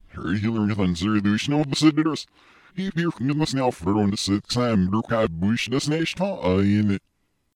Sound Buttons: Sound Buttons View : Evil Backwards Message
backwards-message_DlfsNWA.mp3